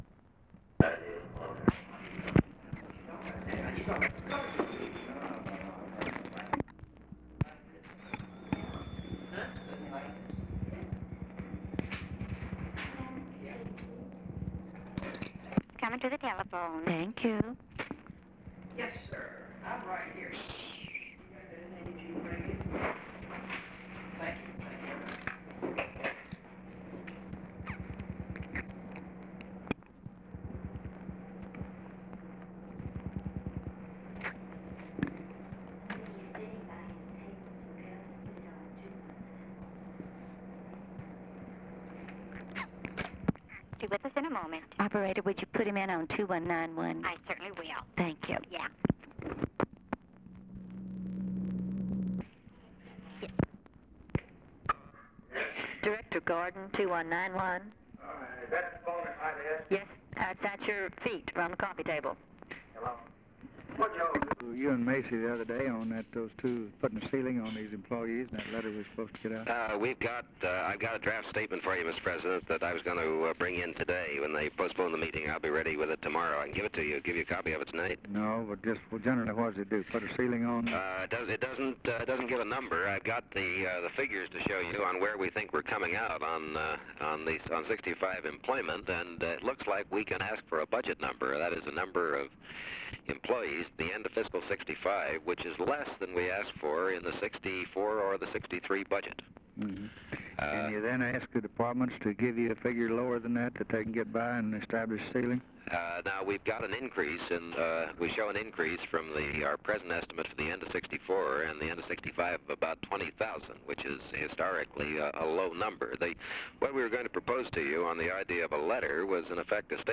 Conversation with KERMIT GORDON, December 9, 1963
Secret White House Tapes